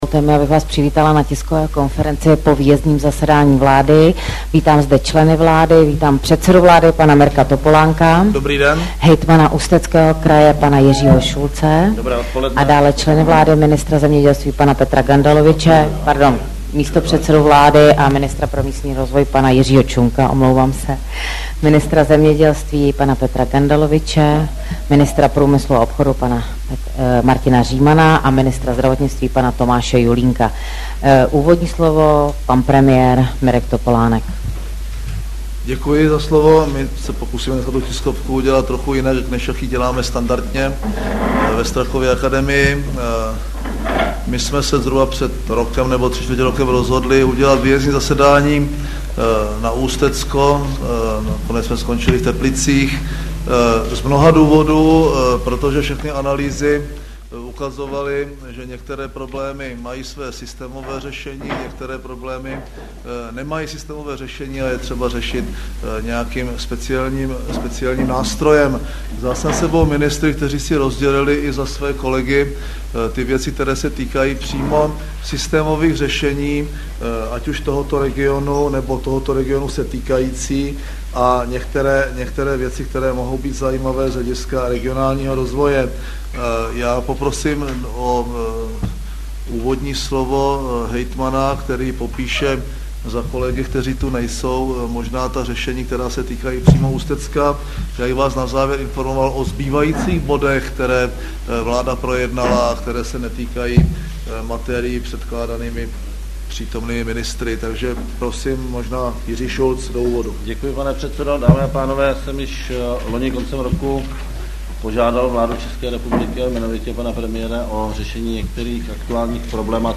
Tisková konference po jednání vlády ČR 21. května 2008 v Teplicích